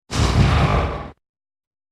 Sound Effects
Dragon Grunt Short Breath